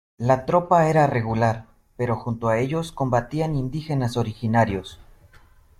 Pronounced as (IPA) /ˈtɾopa/